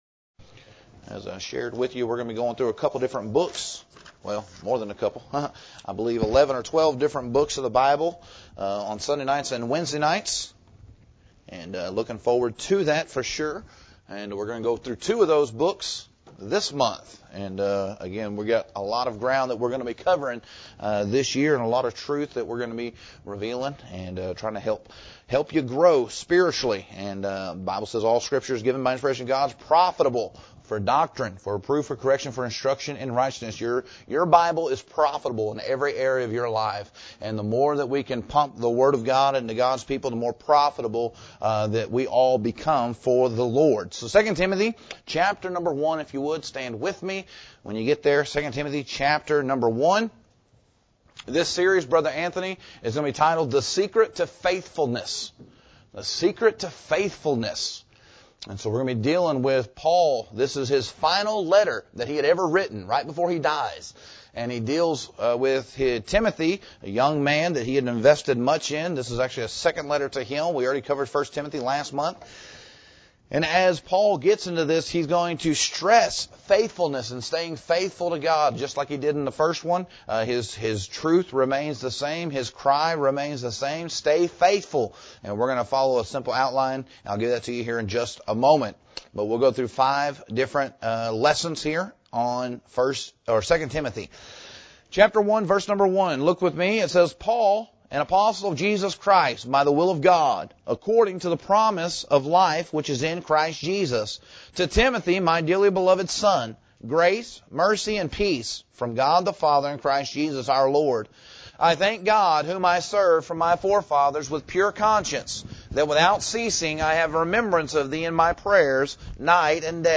This sermon is designed to encourage young adults to grow deeper in their walk with Christ, to understand practical motives for faithfulness, and to embrace the call to both receive and pass on spiritual investment.